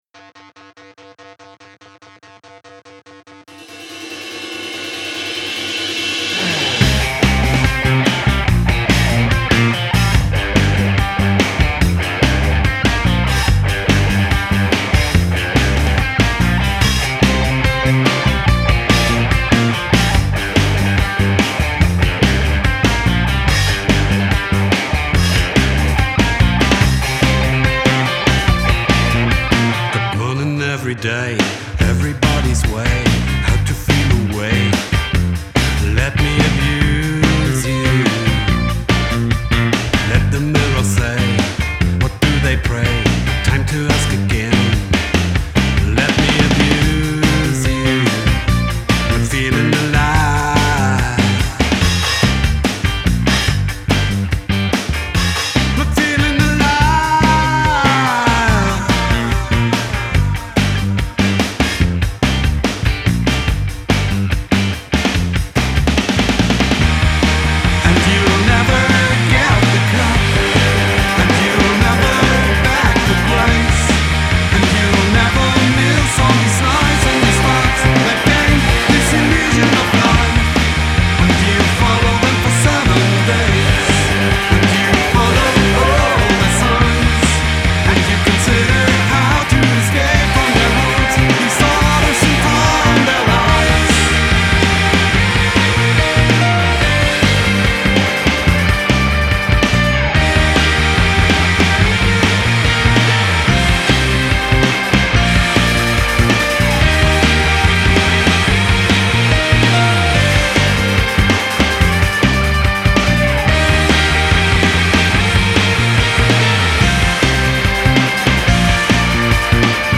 indie rock band
BASS, VOC
Keyboard, VOC
Drums
GUITAR